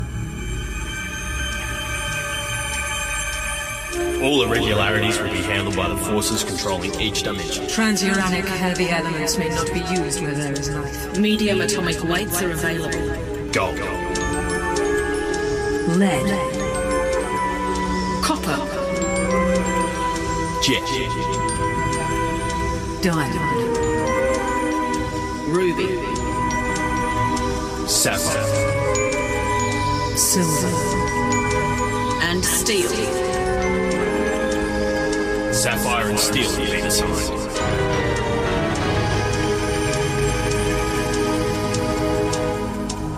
altered opening theme